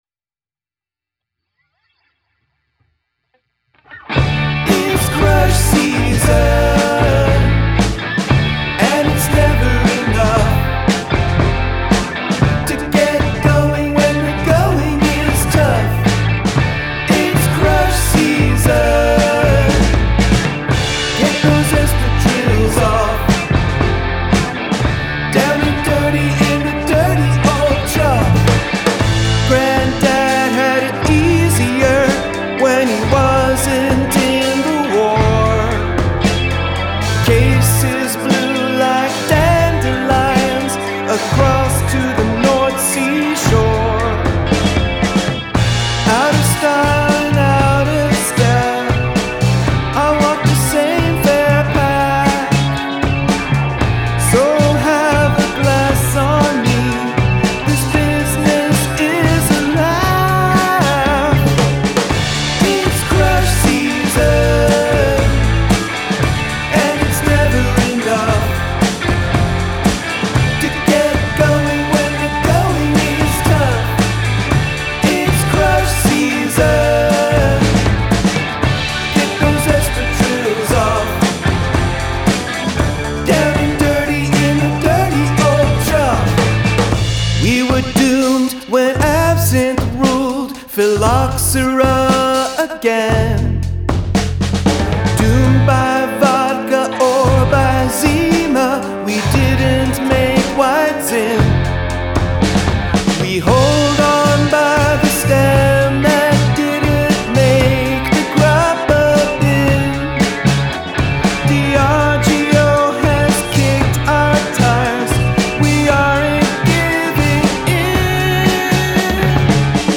Use of Line Cliche
| D, G | Bb, A | D, G | F | D, D/C# | D/B, D/A | (x2)
Verse Part 1 (Descending Line Cliche`)
Verse Part 2 (Ascending Line Cliche`)